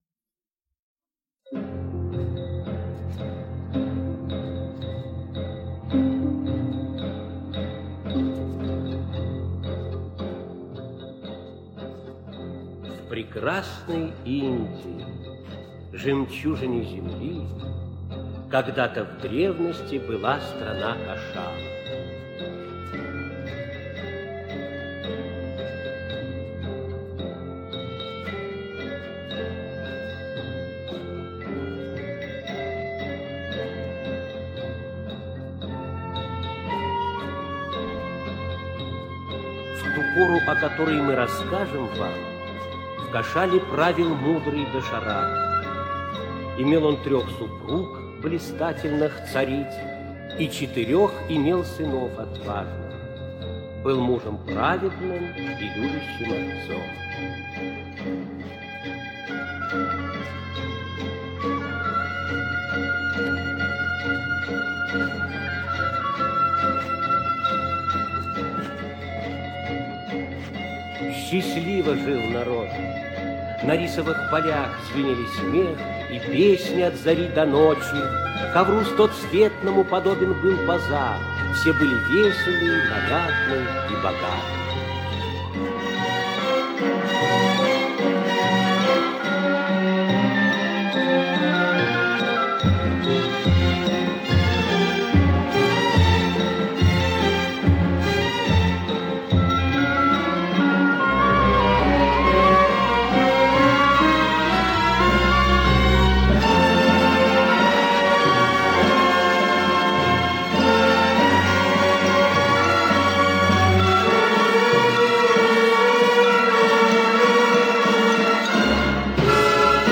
"Рамаяна" Спектакль в 2-х действиях – Лекции и книги Александра Хакимова
ramayana--spektakl-v-2-h-dejstviyah.mp3